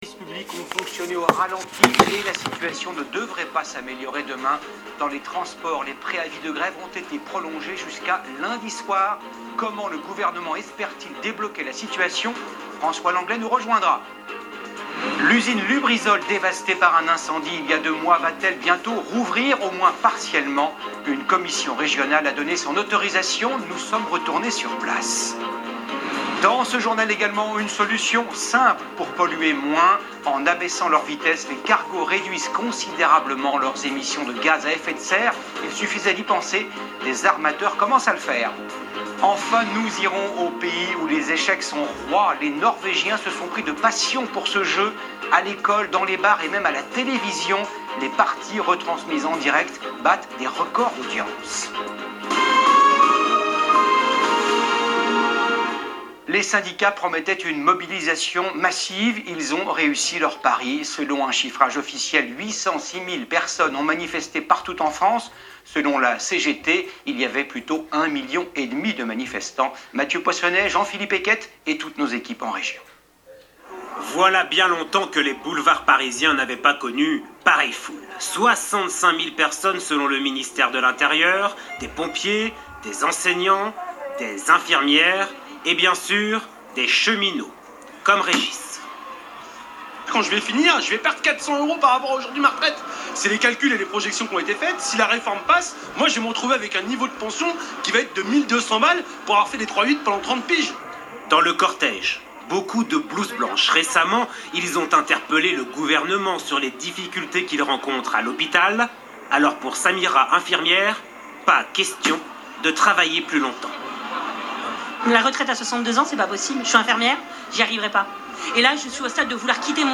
Radio TAMTAM AFRICA Journal de 20H Journal de 20h 05 décembre 2019